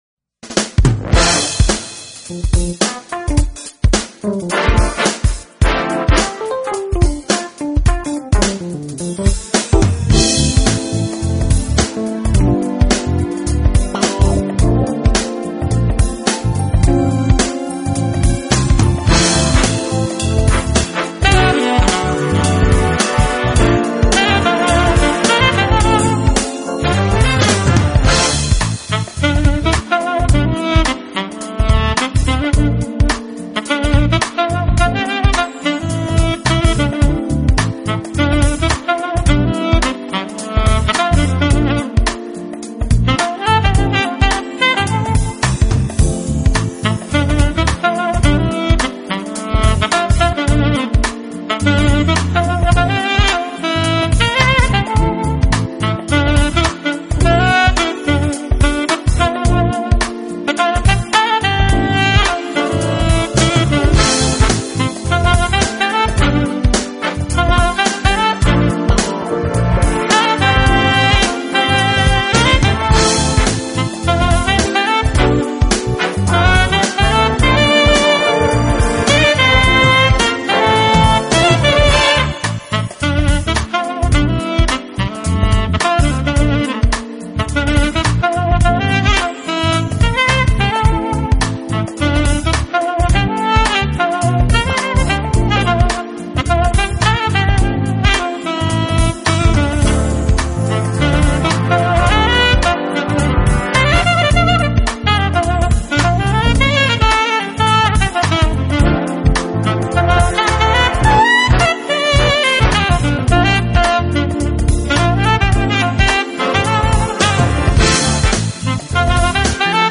音乐类型：爵士
这是张非常好听的萨克斯风爵士